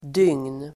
Ladda ner uttalet
Uttal: [dyng:n]